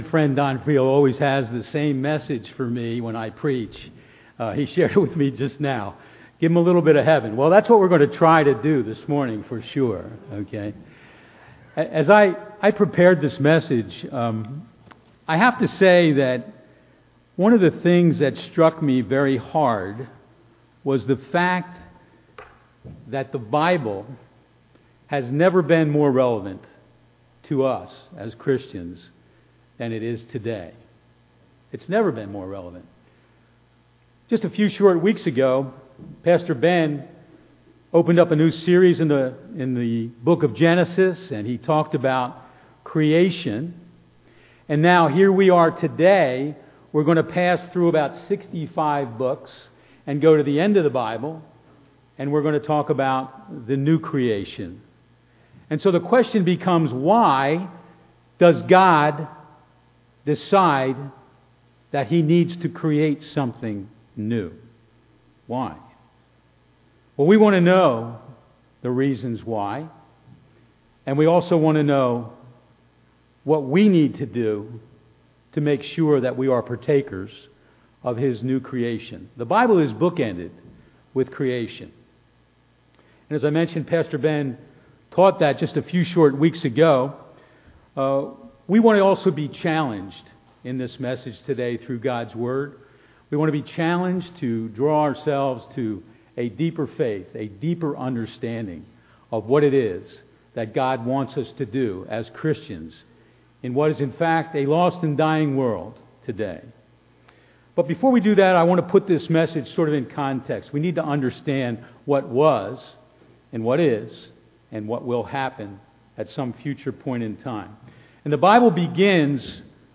Download file | Play in new window | Duration: 46:12 | Speaker: Guest Speaker
2021 Sermons